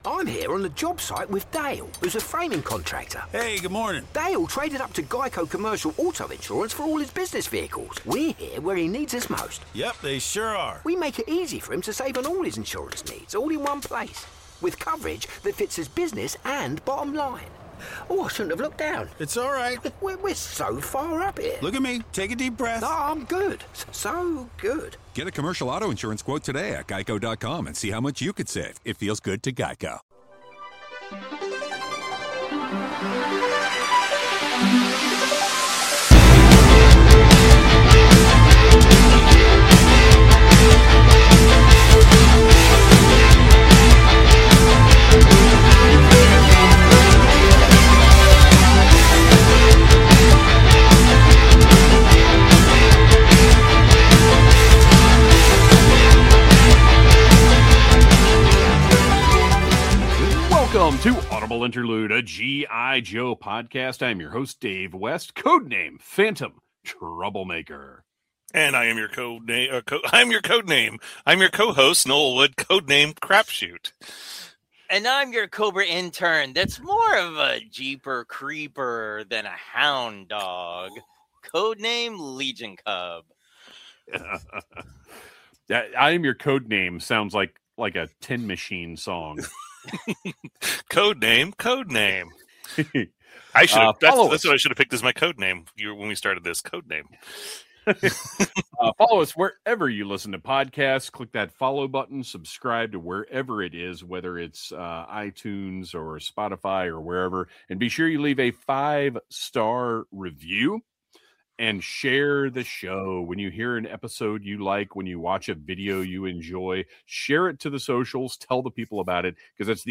We're recruiting you to listen to this podcast hosted by three lifelong Joe fans.